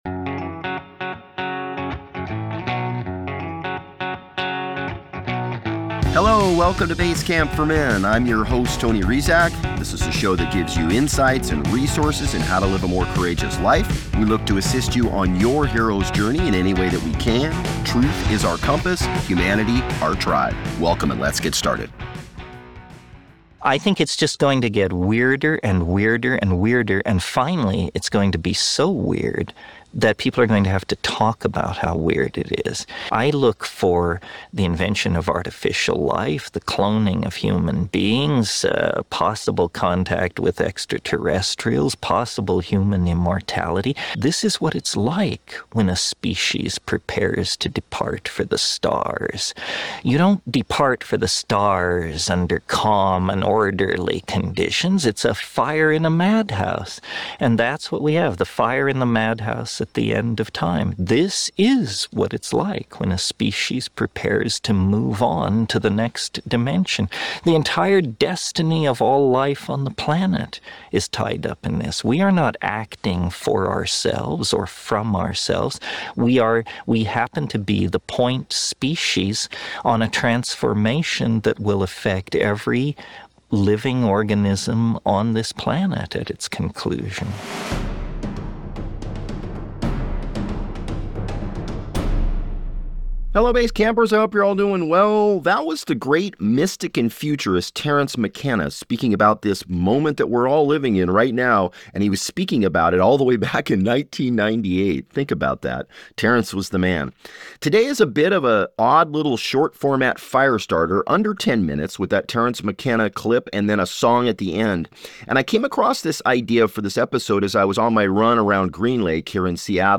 Join Basecamp for a short-format riff just 8.5 minutes long with Terrance McKenna and Pat Benatar.
Today is a bit of an odd little short-format Firestarter episode, under 10 minutes. With that Terrance McKenna audio clip and then a song at the end.